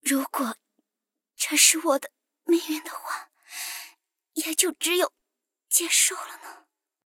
M7牧师被击毁语音.OGG